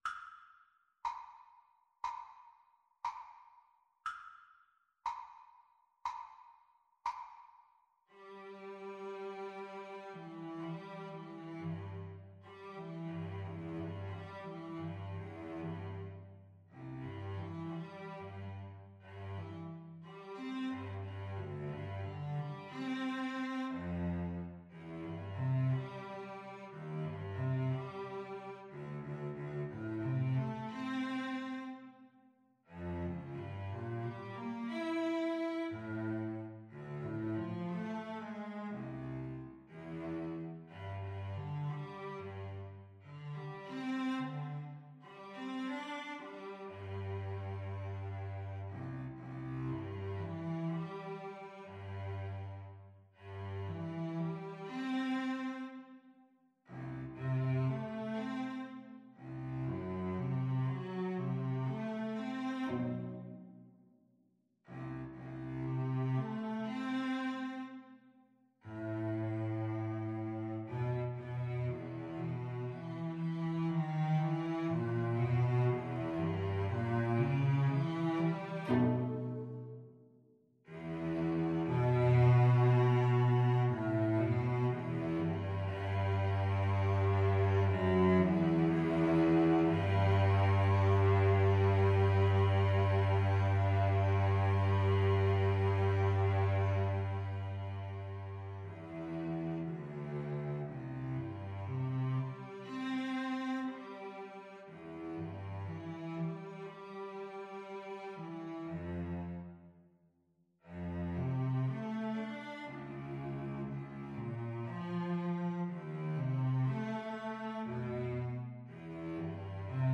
Sehr langsam